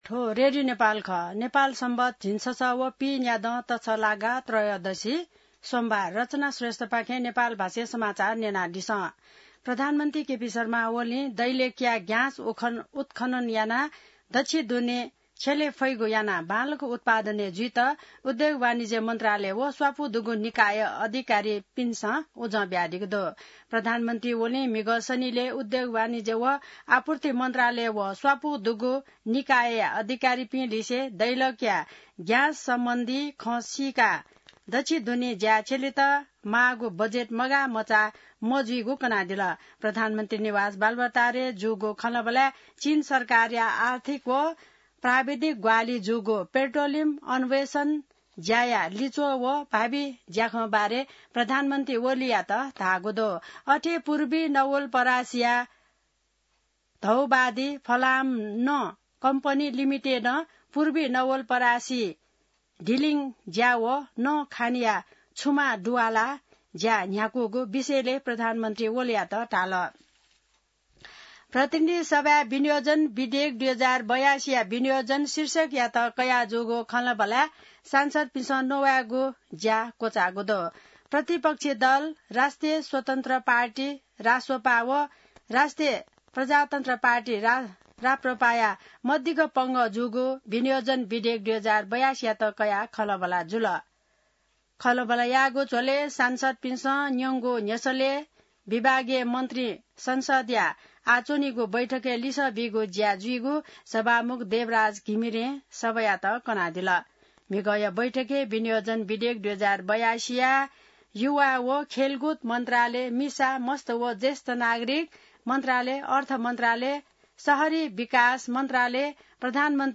नेपाल भाषामा समाचार : ९ असार , २०८२